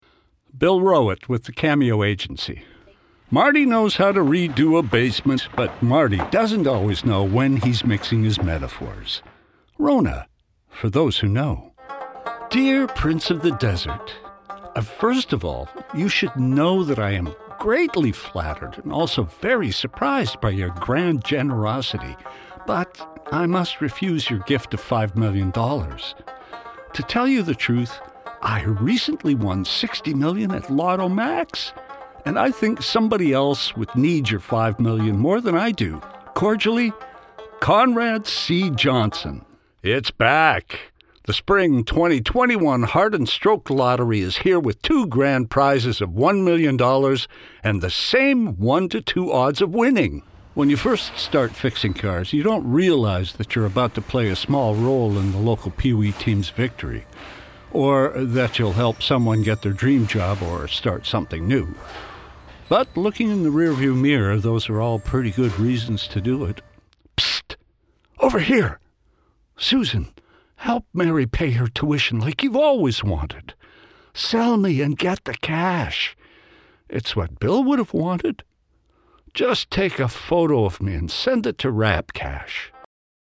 Démo voix - ANG